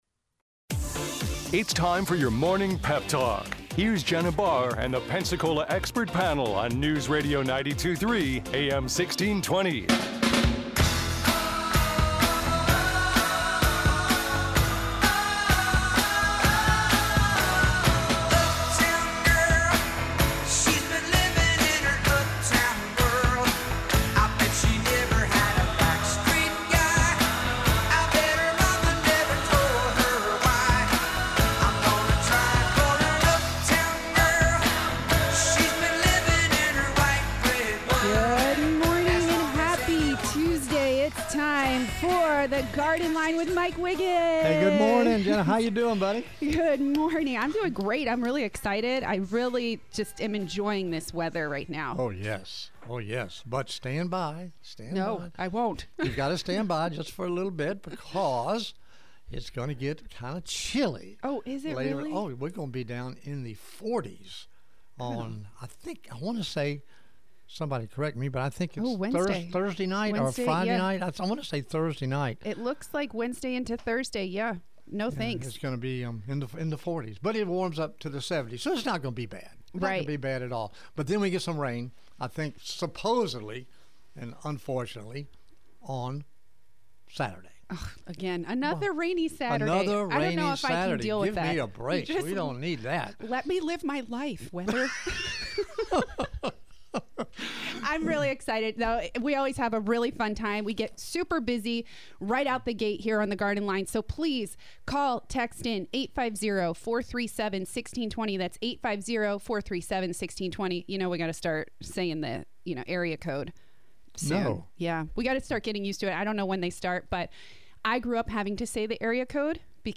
A caller asks a unique question and listeners join in to answer!!